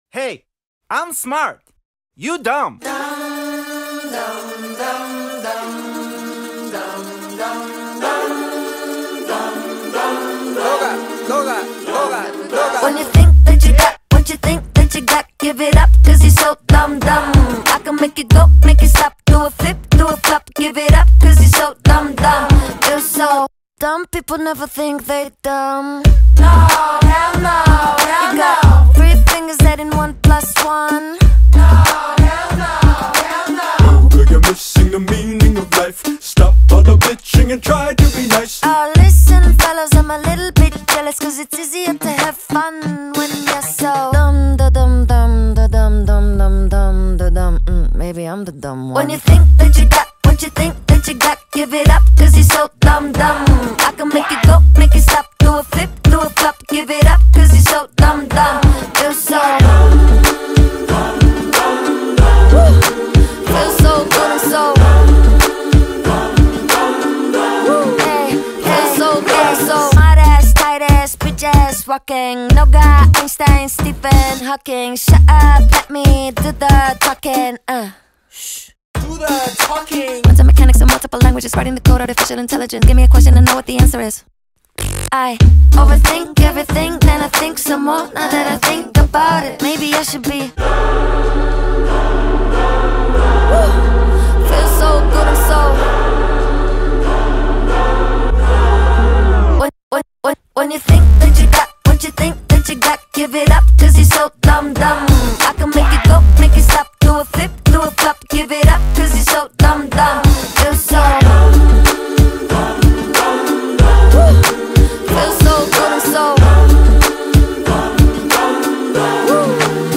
الکترو پاپ